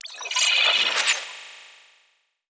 Digital Bling Alert 3.wav